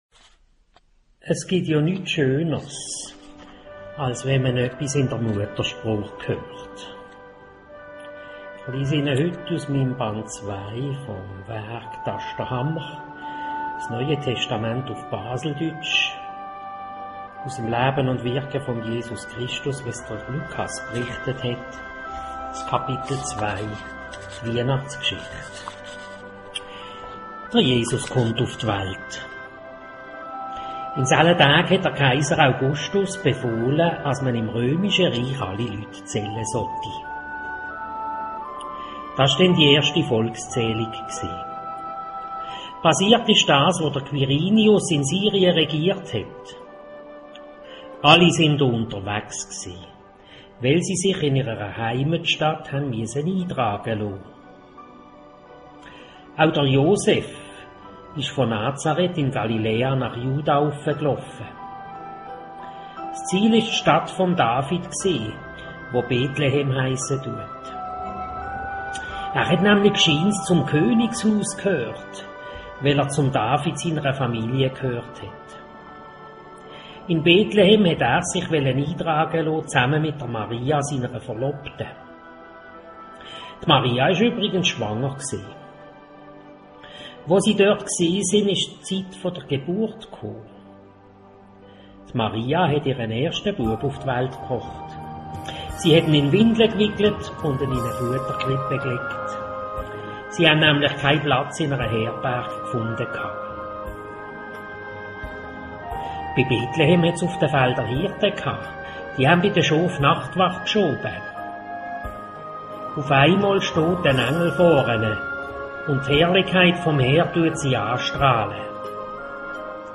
D'Wienachtsgschicht uf Baseldütsch